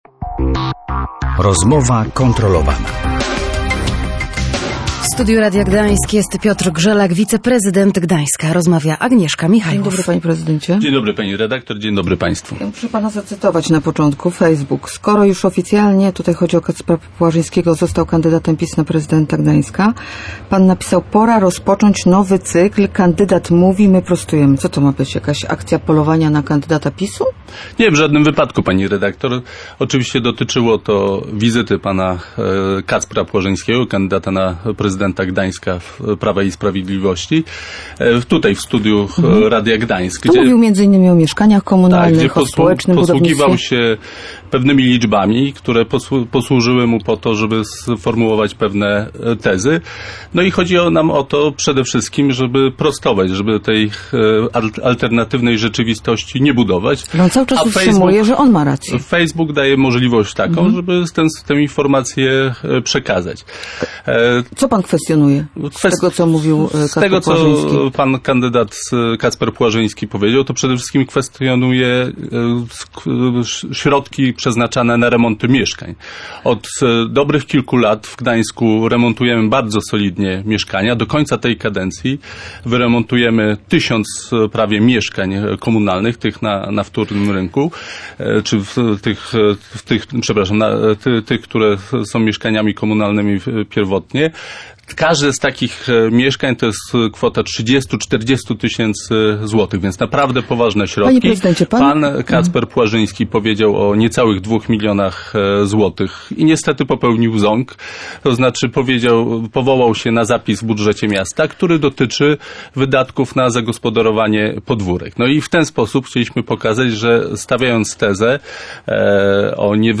Popełnił błąd, bo powołał się na zapis w budżecie miasta, który dotyczy wydatków na zagospodarowanie podwórek – mówił w Rozmowie Kontrolowanej Piotr Grzelak, wiceprezydent Gdańska.